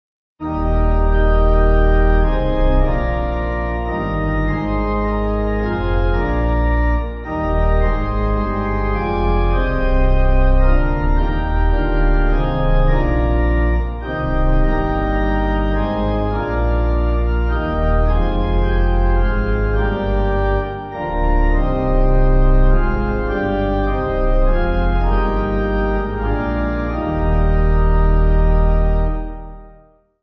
8.8.8.8 with Refrain
Organ
(CM)   6/Eb